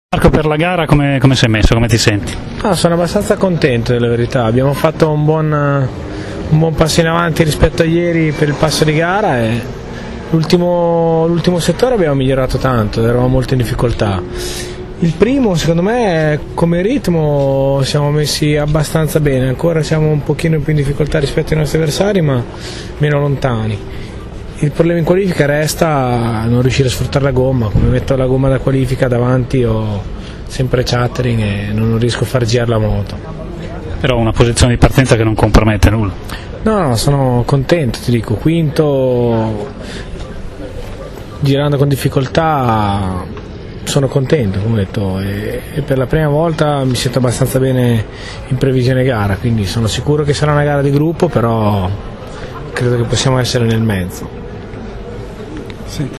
ascolta la sua intervista) è soddisfatto non solo della seconda fila, ma soprattutto perché dispone di un buon passo di gara e in Superpole non è riuscito a fare meglio in quanto la sua BMW non riesce a sfruttare a dovere la gomma tenera da tempo.